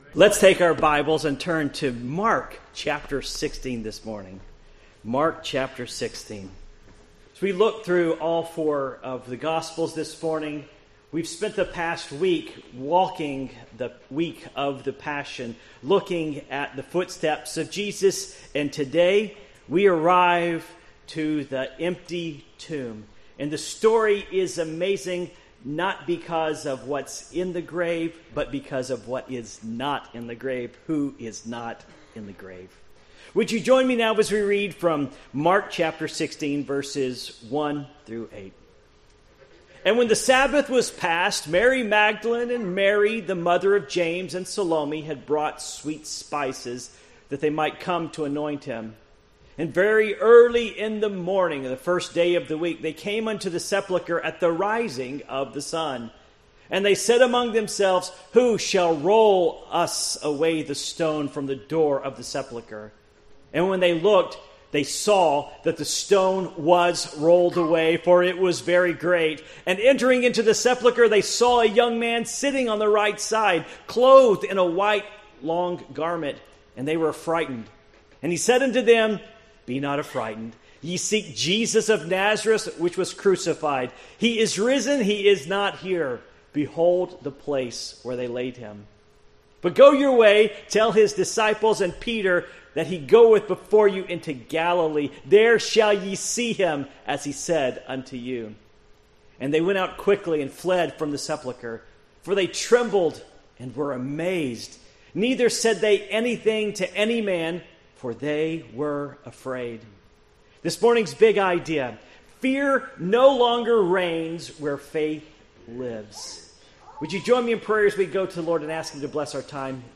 Passage: Mark 16:1-8 Service Type: Morning Worship